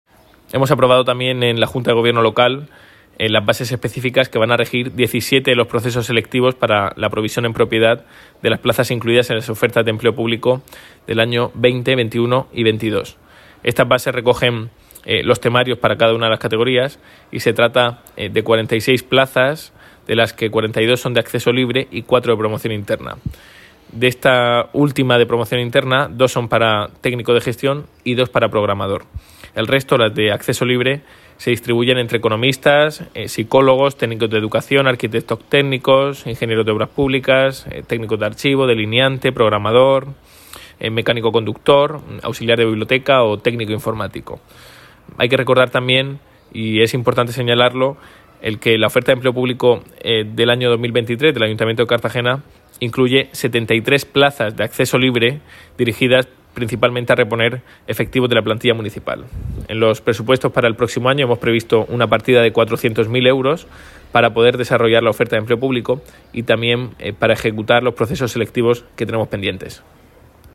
Enlace a Declaraciones de Ignacio Jáudenes